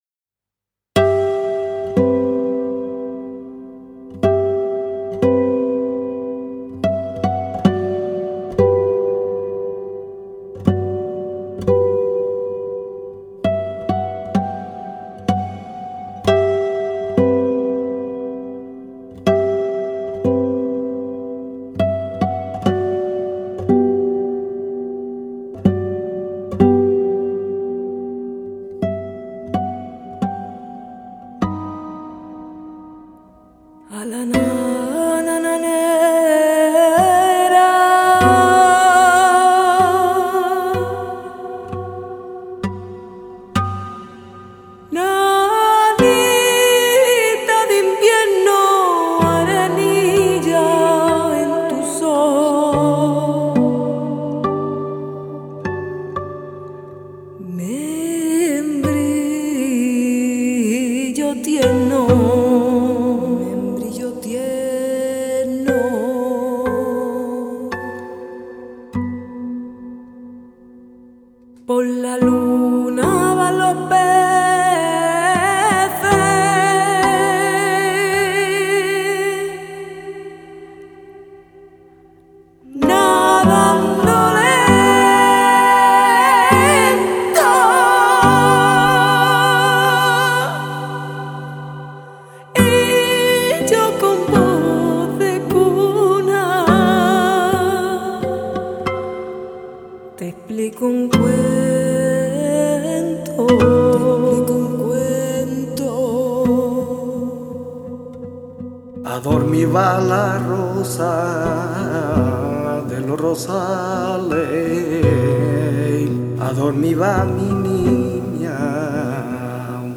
guitare
percussions